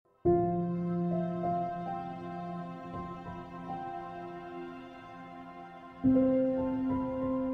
🎧 Listening to Mp3 Sound Effect Feeling stressed? 🎧 Listening to calming music can work wonders by lowering cortisol levels and massaging your mind. 🧠💆‍♀ Take a moment for yourself today and let the soothing sounds bring you peace.